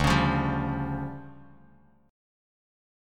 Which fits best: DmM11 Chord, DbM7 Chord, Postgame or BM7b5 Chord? DmM11 Chord